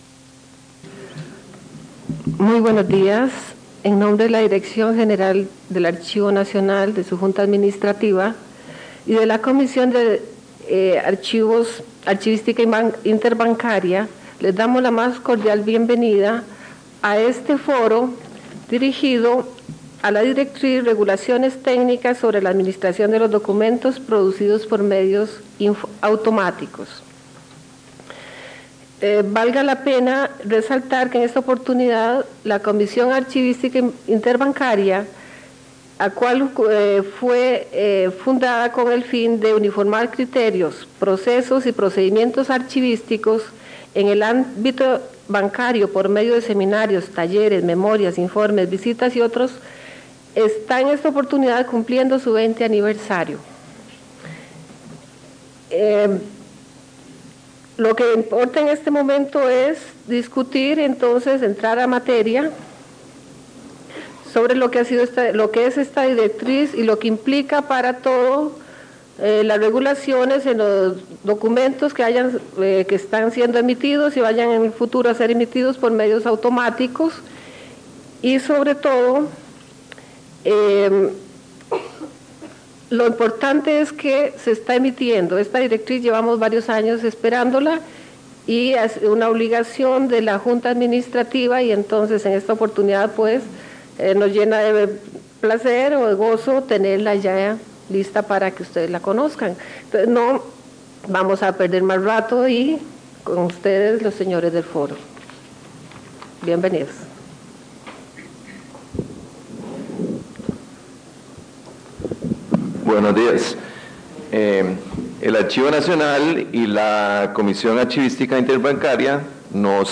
Grabación del Seminario Archivístico Interbancario sobre la directriz con las regulaciones técnicas de los documentos producidos por medios automáticos - Archivo Nacional de Costa Rica
Notas: Casete de audio y digital